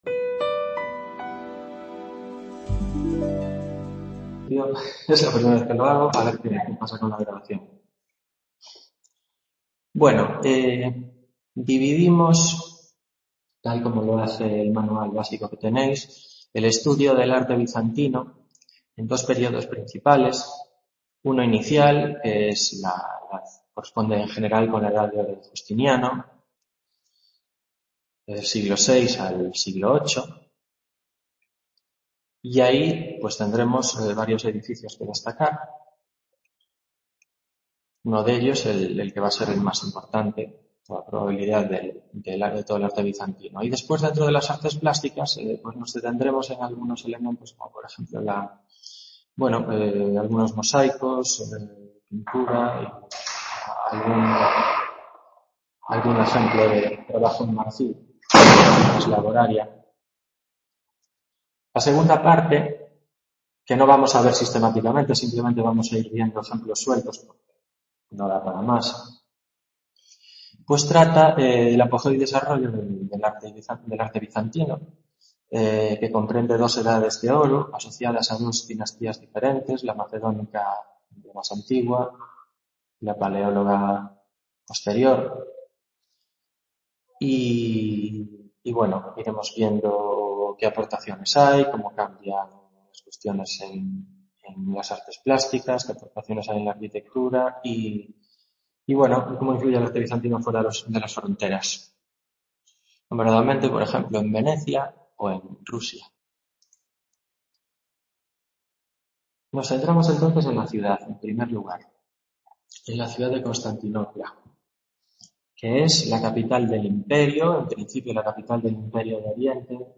Tutoría de los temas 2-3: Arte bizantino de los siglos VI al XV